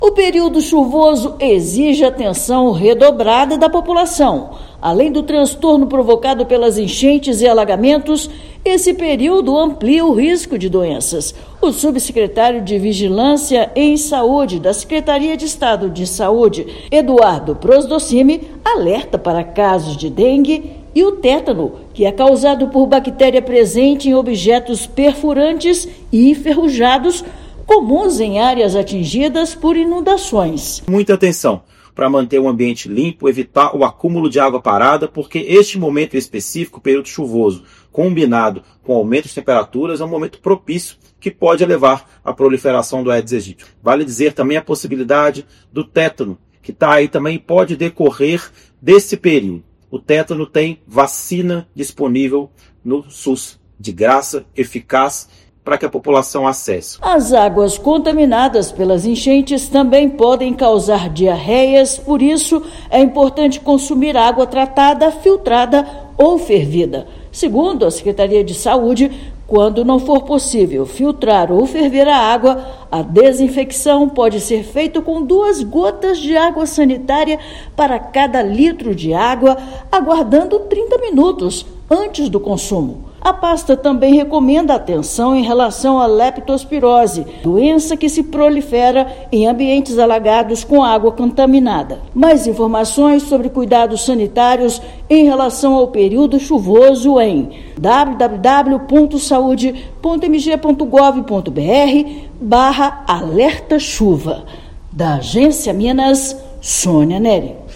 Verão exige atenção redobrada com doenças, vacinação, água potável e prevenção de riscos à saúde. Ouça matéria de rádio.